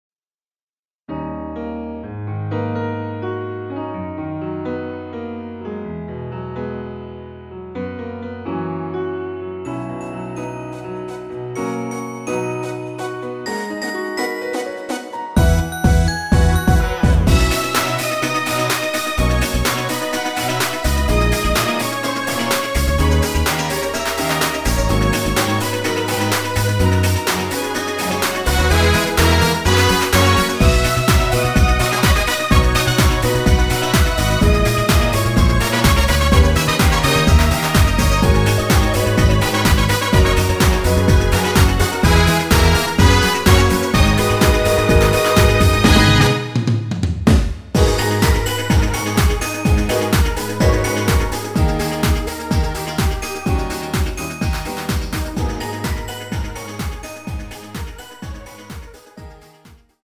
원키 멜로디 포함된 MR 입니다.(미리듣기 참조)
앞부분30초, 뒷부분30초씩 편집해서 올려 드리고 있습니다.
중간에 음이 끈어지고 다시 나오는 이유는